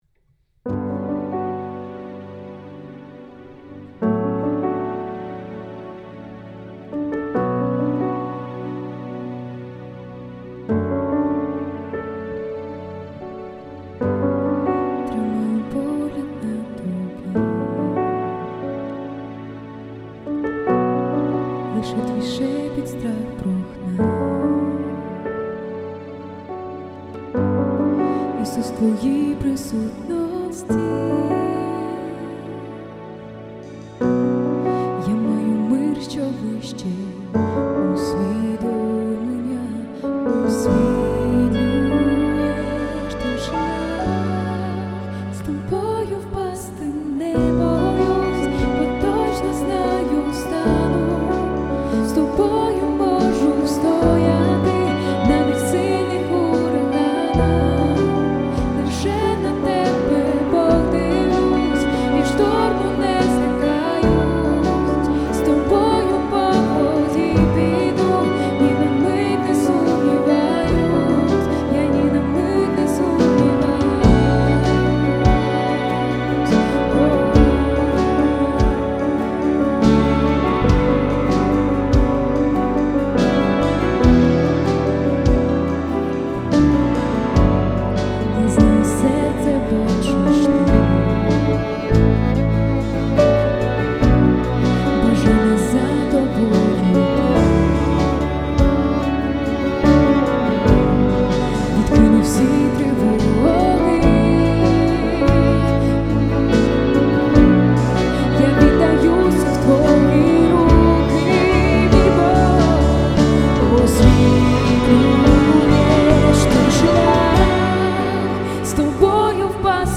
24 просмотра 12 прослушиваний 0 скачиваний BPM: 144